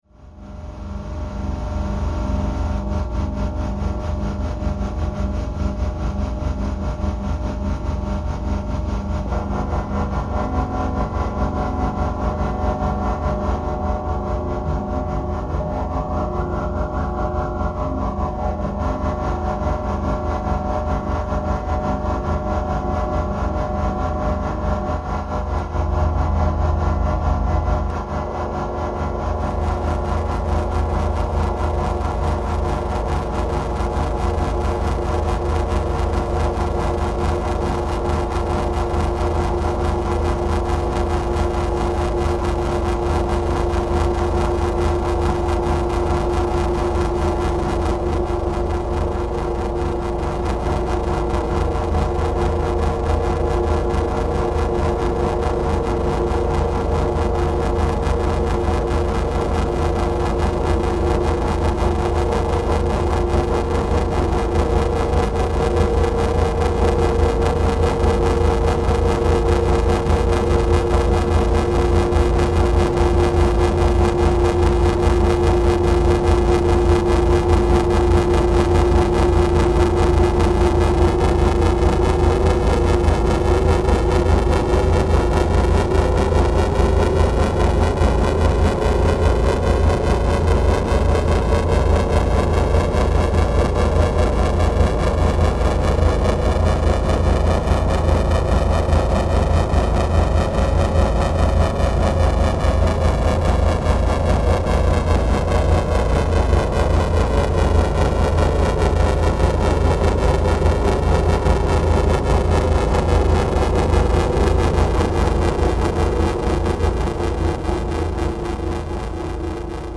Flat Holm lighthouse - processed version